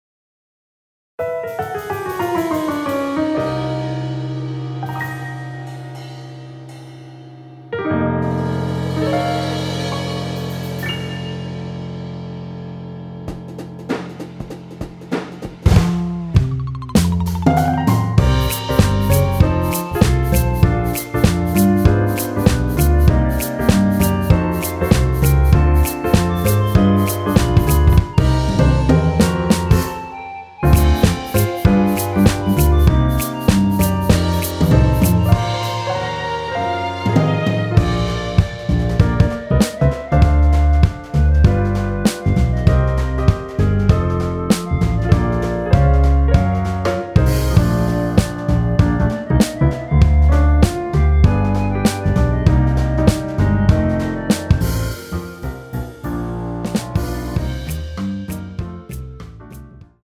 원키에서(-1)내린 멜로디 포함된 MR입니다.(미리듣기 확인)
Bb
앞부분30초, 뒷부분30초씩 편집해서 올려 드리고 있습니다.
중간에 음이 끈어지고 다시 나오는 이유는